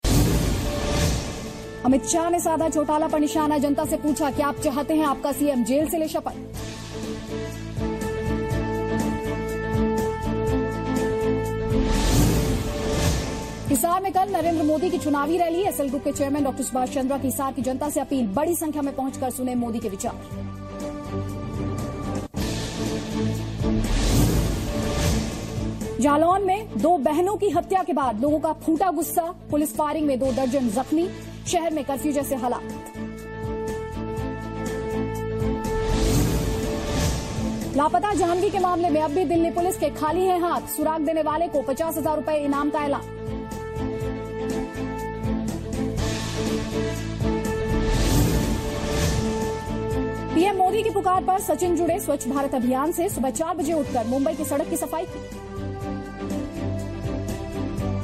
Top ten news at 8 pm